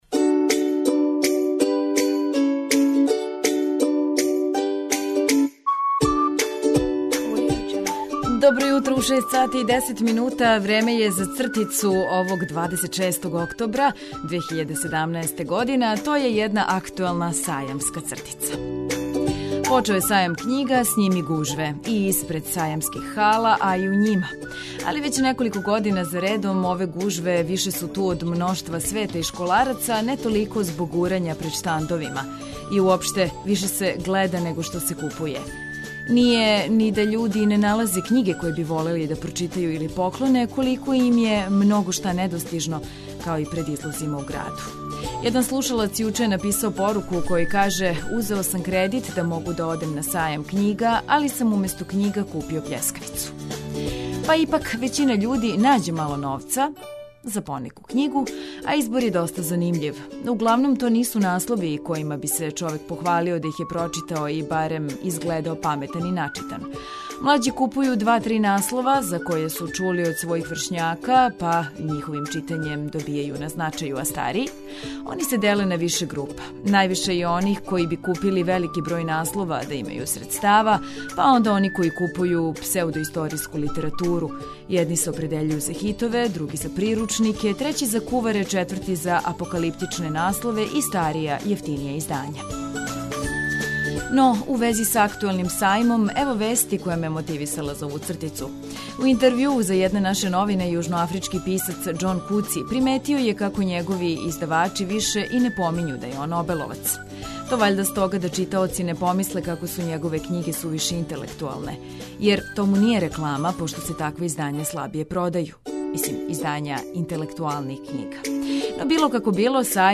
За наш радио говори фудбалер Лука Миливојевић.